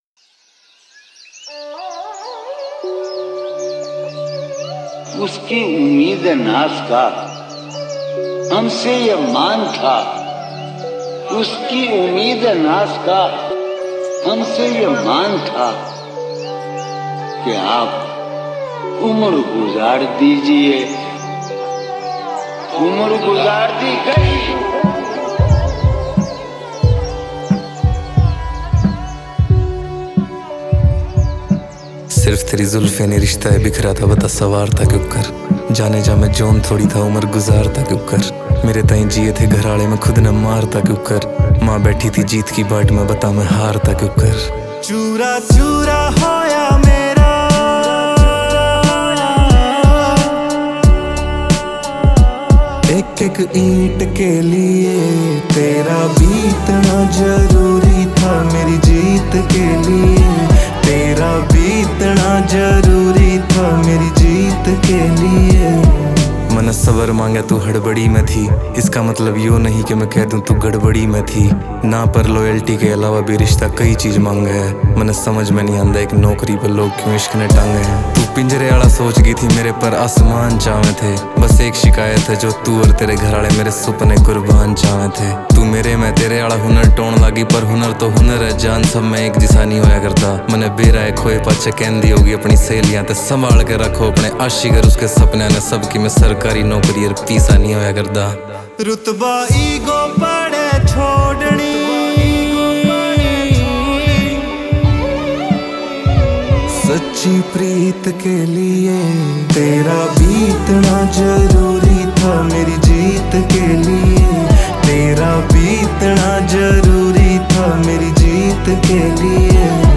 haryanvi songs